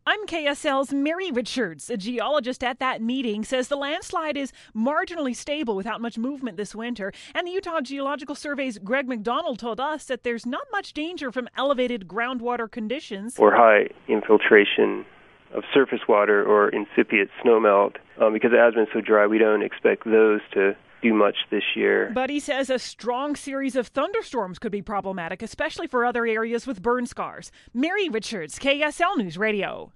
Geologists talk about North Salt Lake landslide
A meeting Tuesday night brought together residents, developers and the city. This report looks at what geologists think of the landslide danger.